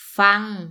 – fang